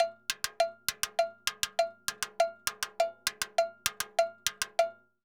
Timbaleta_Salsa 100_2.wav